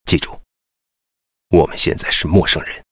文件 文件历史 文件用途 全域文件用途 Bk2_fw_03.ogg （Ogg Vorbis声音文件，长度2.9秒，73 kbps，文件大小：26 KB） 源地址:游戏语音 文件历史 点击某个日期/时间查看对应时刻的文件。